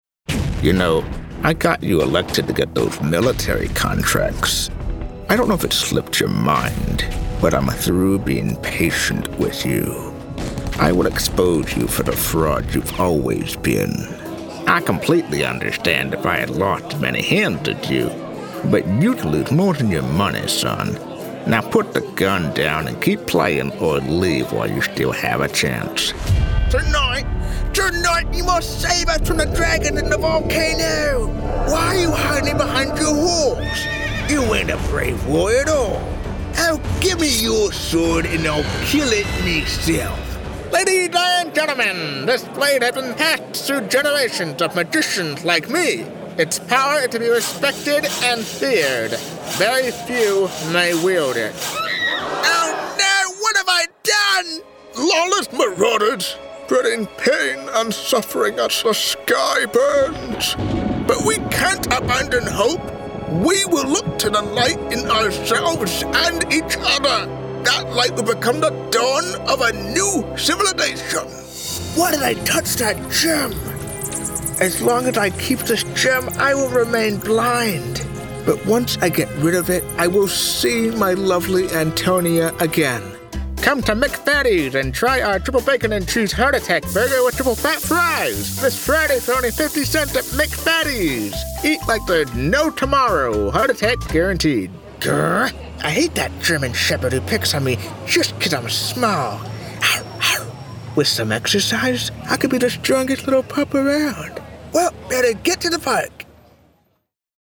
Hear the bold voices featured in Ford commercials.